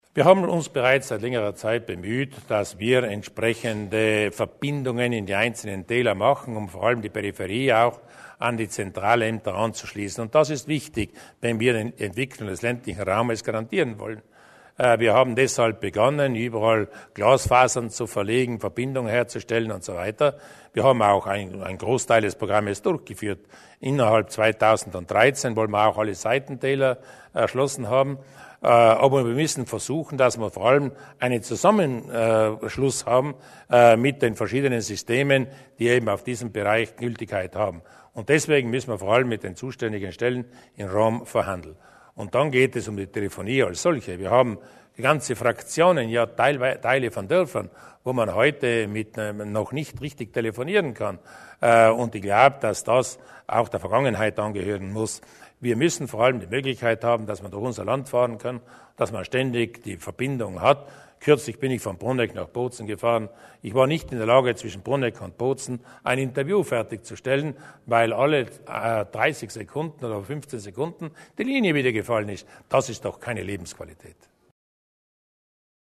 Landeshauptmann Durnwalder zu den Problemen bei den mobilen Telefondiensten